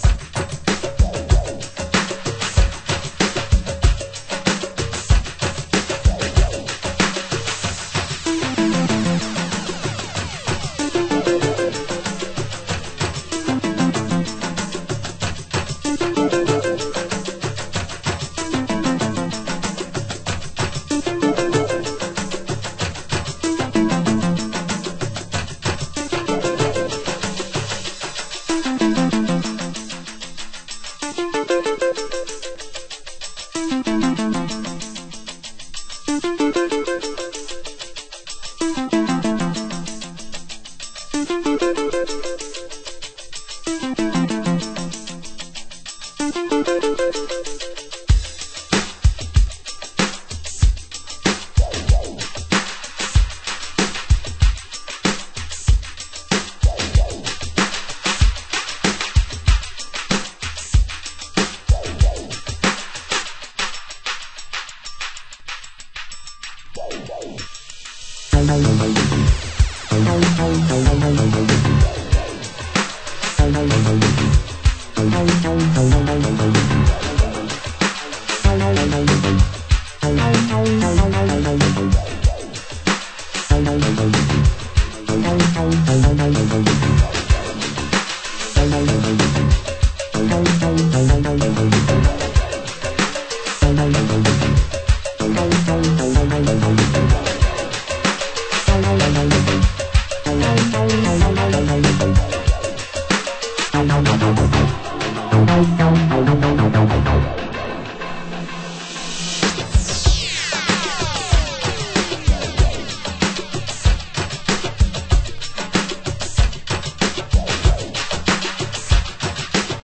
盤質：A1のアウトロにプレスノイズ 有/B2のアウトロに盤面汚れによるノイズ